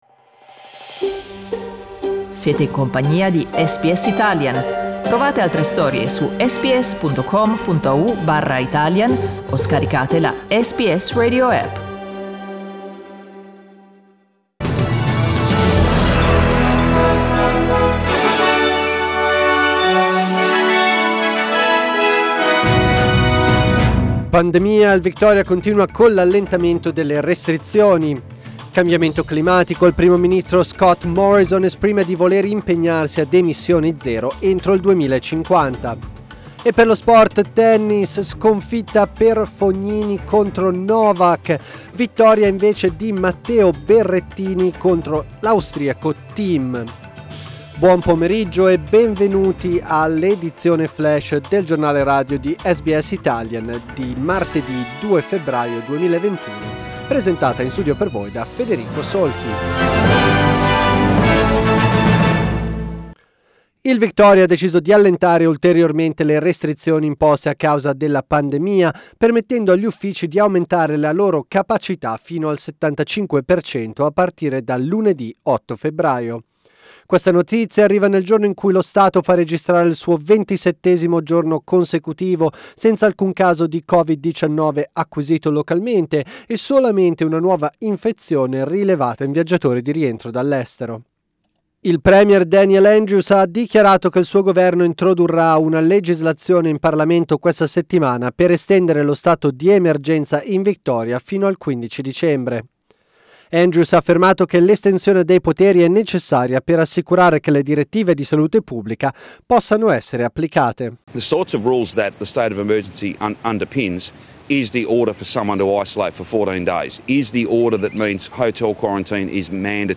News Flash Tuesday 2 February
Our news update in Italian.